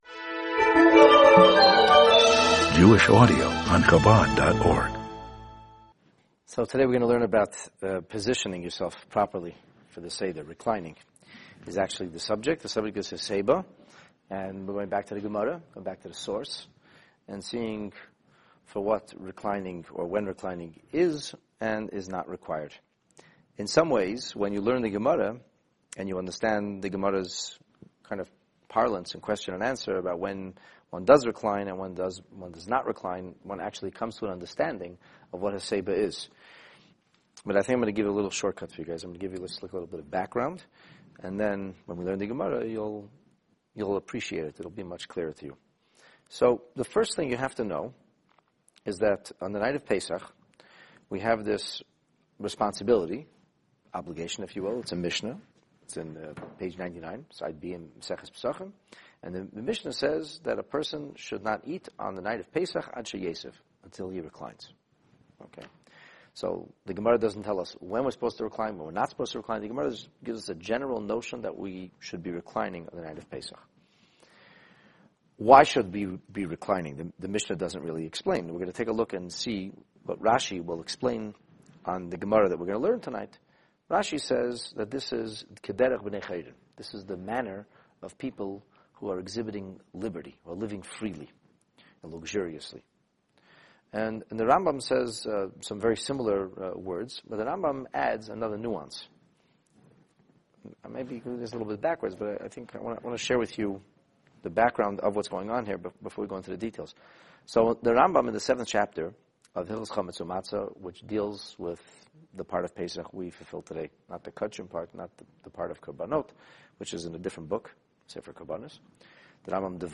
The Talmud on the Pesach Seder, Lesson 2 (Daf/Page 108a) This Talmud class analyzes the reclining position assumed during segments of the Seder observances.